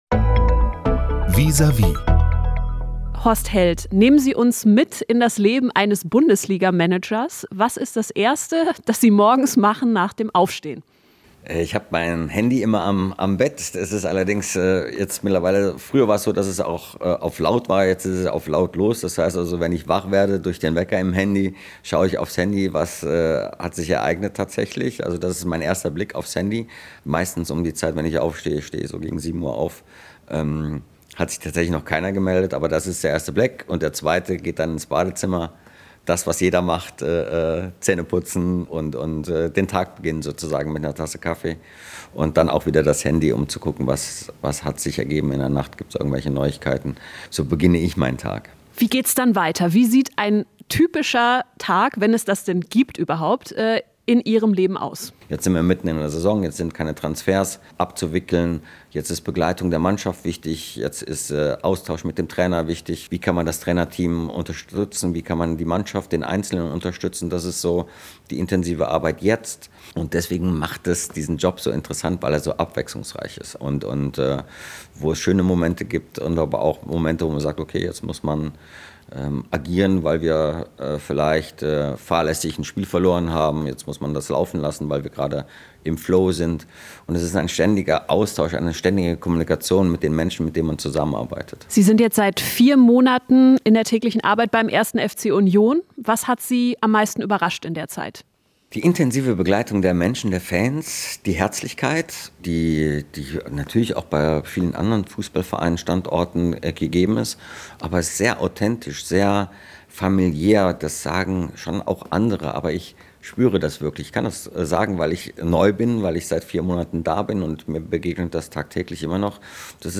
Seit vier Monaten ist Horst Heldt Geschäftsführer beim 1.FC Union Berlin. Wie er seinen Job versteht und was ihn an Verein und Fans besonders begeistert, erzählt er im Vis à vis.